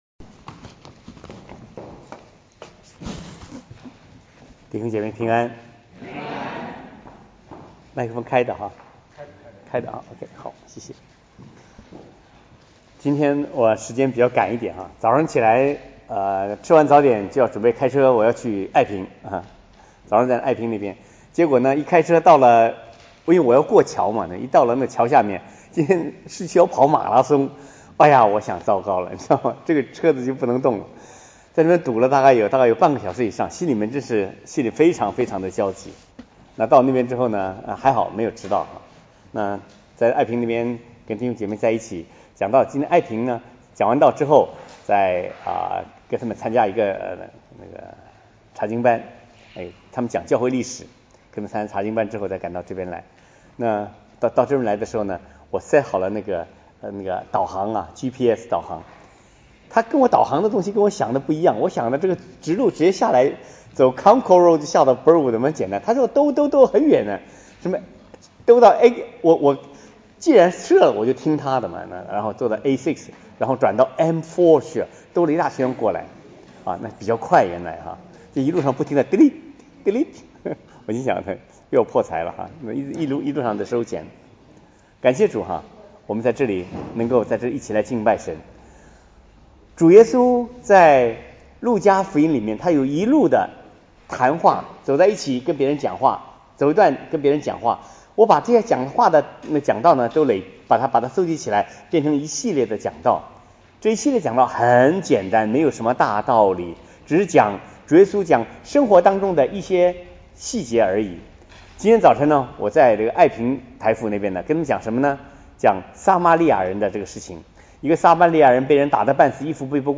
提摩太後書1：3-5 證道系列: 其它 講員